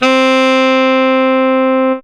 37a01sax-c.aif